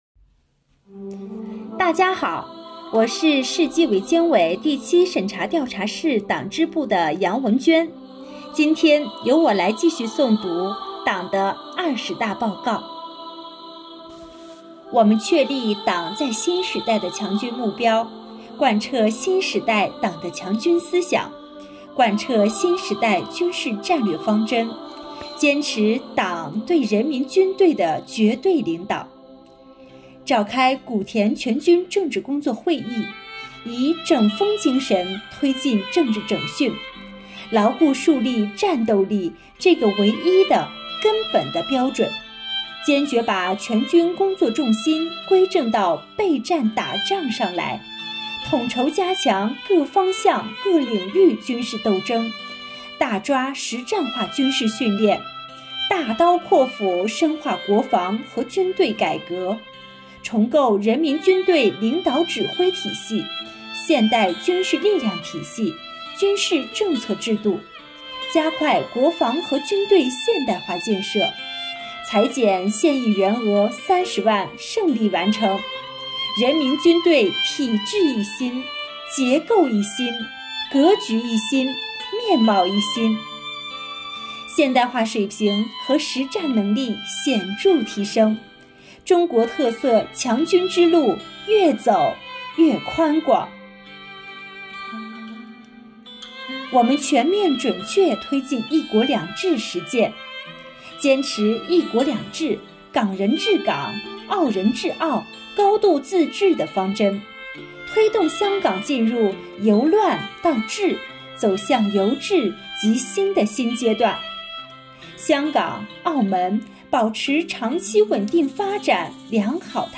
本期诵读人